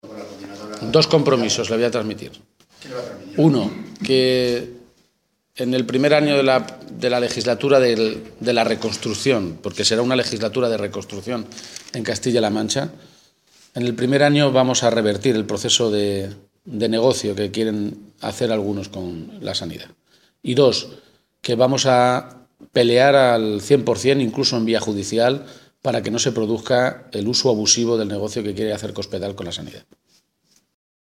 Emiliano García-Page ha realizado estas declaraciones antes de reunirse con la Coordinadora en Defensa del Hospital Público de Almansa, a quienes ha transmitido dos compromisos: “que en el primer año de legislatura de reconstrucción de Castilla-La Mancha vamos a revertir el proceso de negocio que quieren hacer algunos con la sanidad. También pelearemos en vía judicial para que no se produzca el uso abusivo del negocio con la sanidad que quiere hacer Cospedal”.